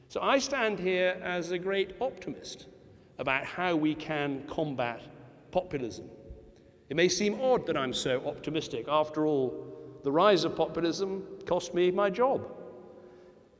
Lecture at DePauw University, Indiana, 8th Dec 2016